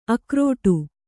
♪ akrōṭu